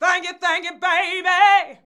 THANK   YA.wav